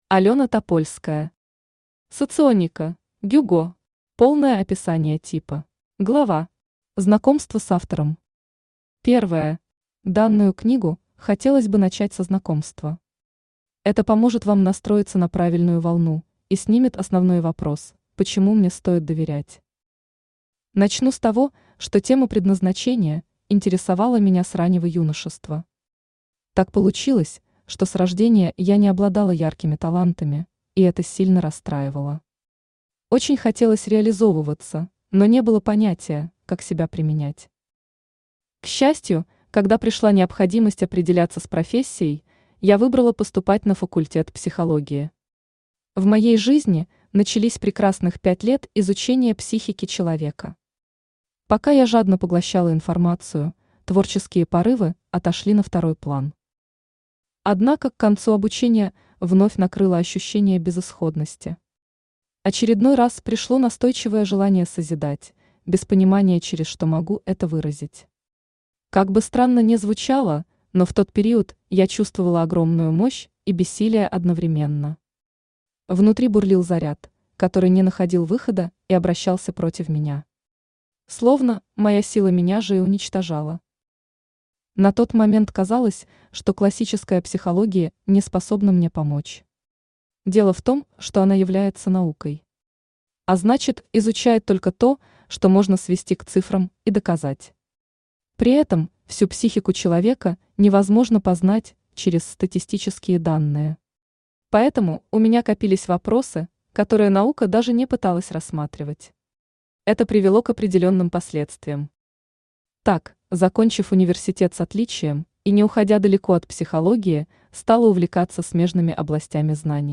Полное описание типа Автор Алена Топольская Читает аудиокнигу Авточтец ЛитРес.